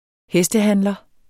Udtale [ ˈhεsdəˌhanlʌ ]